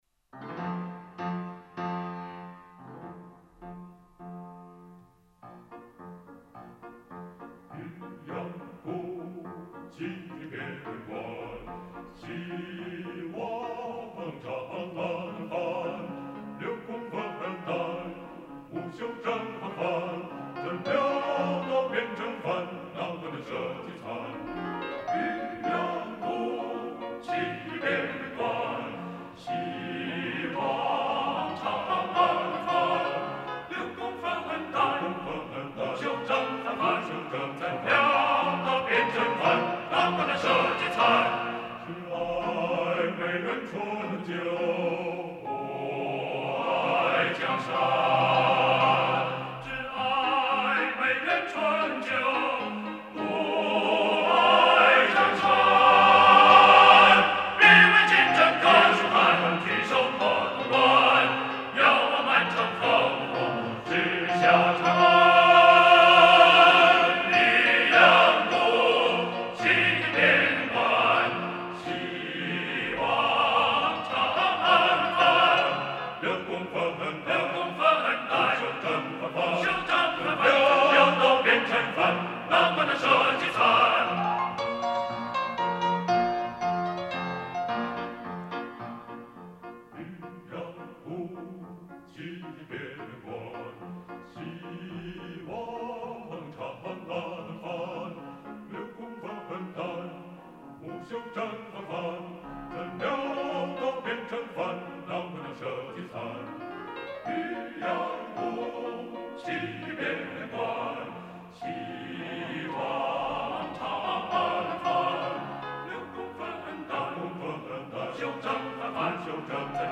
男声四声部合唱